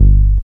06SYN.BASS.wav